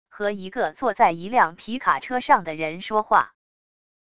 和一个坐在一辆皮卡车上的人说话wav音频